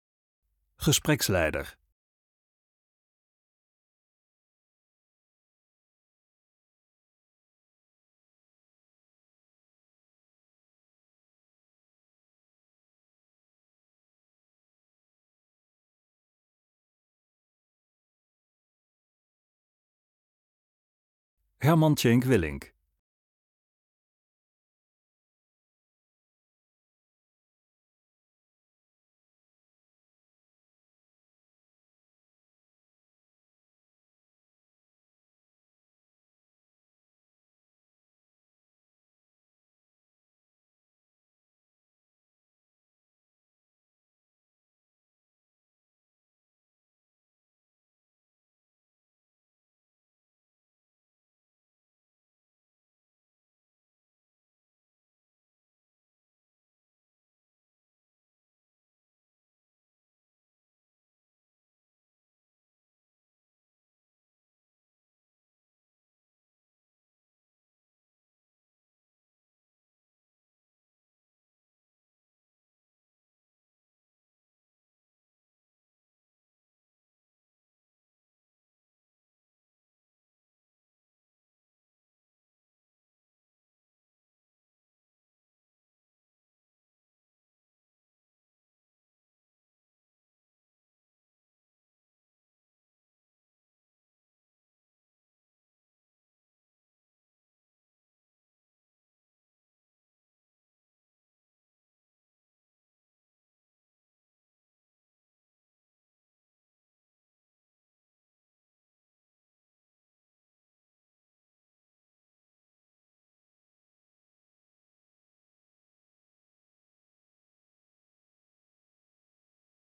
Persconferentie informateur Tjeenk Willink
Inleidende verklaring informateur Tjeenk Willink